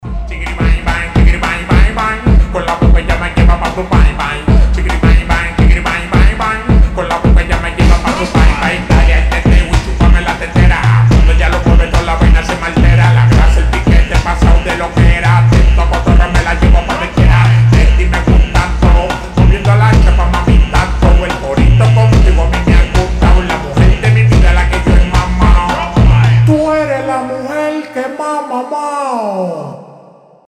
Танцевальные рингтоны
Рэп рингтоны , Рингтоны техно , Ритмичные
Реггетон